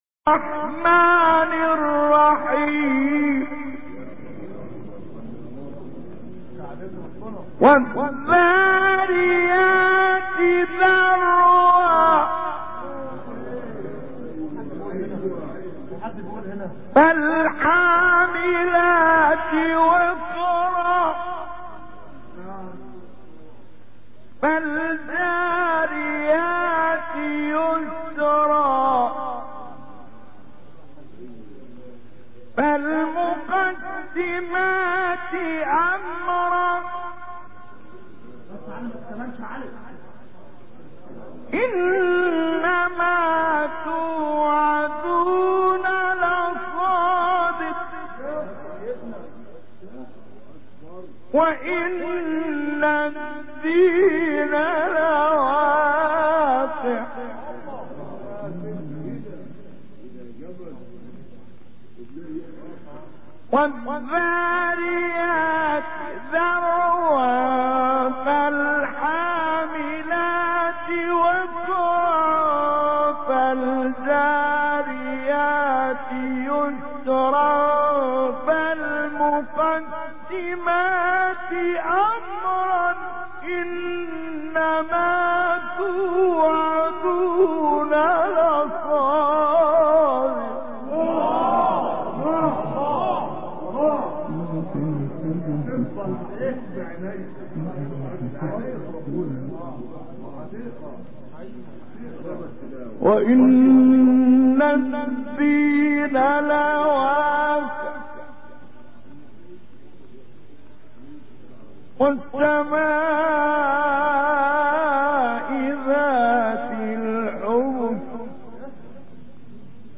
گروه شبکه اجتماعی: مقاطع صوتی از تلاوت قاریان بنام و برجسته جهان اسلام که در شبکه‌های اجتماعی منتشر شده است، می‌شنوید.
سوره ذاریات در مقام نهاوند